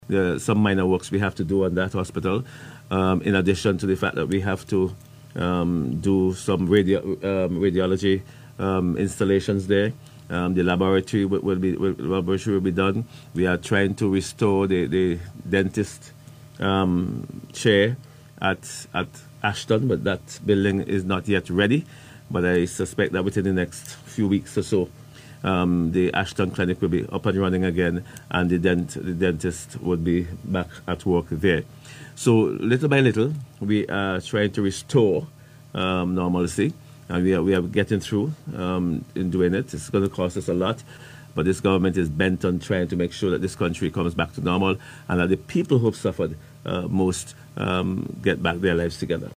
Minister of Health, Wellness and the Environment St Clair Prince, speaking on radio recently said the hospital in Clifton, Union island is back up and running with only some minor works left to be done.